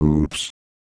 Worms speechbanks
oops.wav